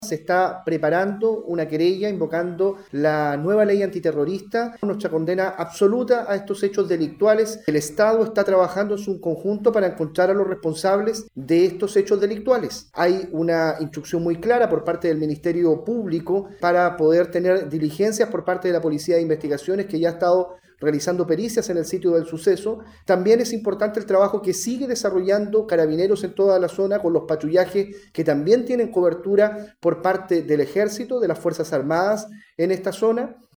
Desde el Ejecutivo, el delegado Presidencial del Bío Bío, Eduardo Pacheco, señaló que se presentará una querella, sustentándola en la nueva Ley Antiterrorista.